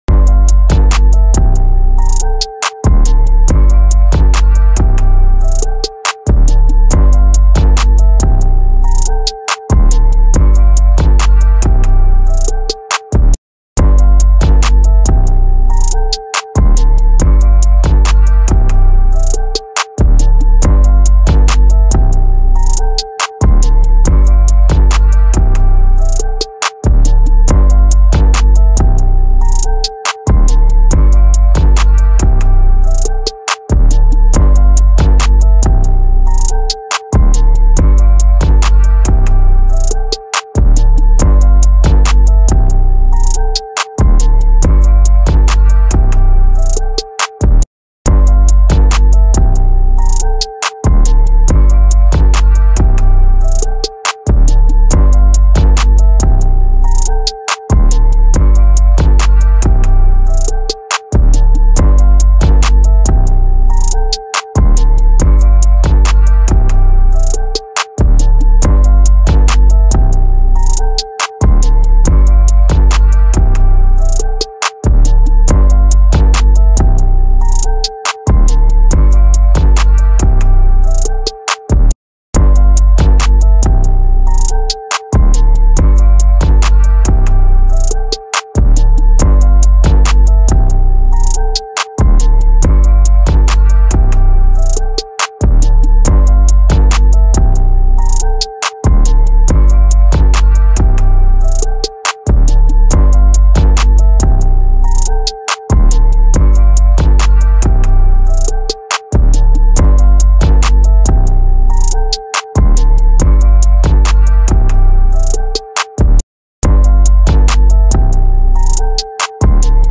official instrumental remake